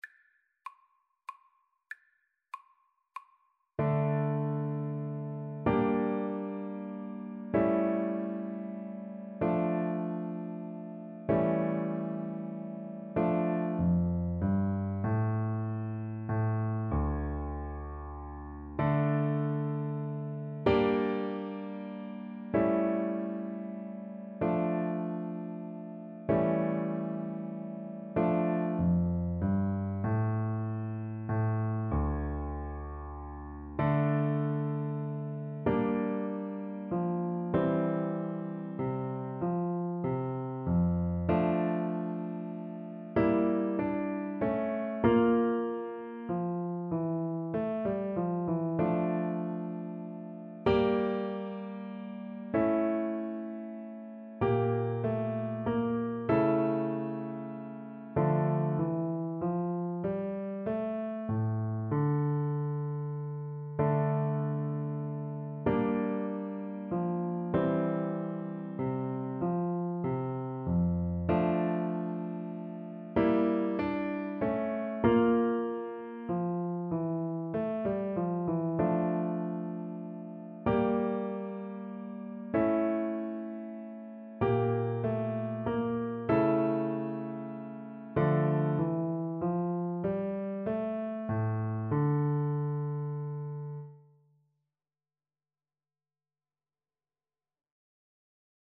Play (or use space bar on your keyboard) Pause Music Playalong - Piano Accompaniment Playalong Band Accompaniment not yet available transpose reset tempo print settings full screen
D minor (Sounding Pitch) E minor (Clarinet in Bb) (View more D minor Music for Clarinet )
Classical (View more Classical Clarinet Music)